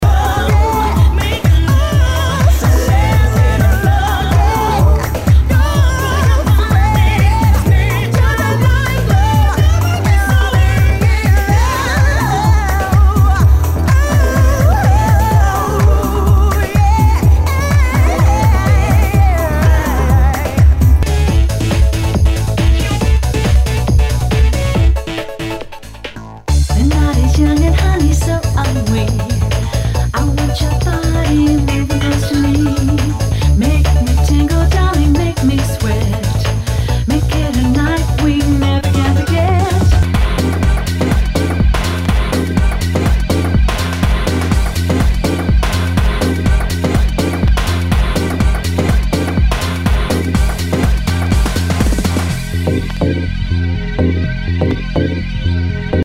HOUSE/TECHNO/ELECTRO